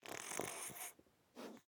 Minecraft Version Minecraft Version snapshot Latest Release | Latest Snapshot snapshot / assets / minecraft / sounds / mob / fox / sleep4.ogg Compare With Compare With Latest Release | Latest Snapshot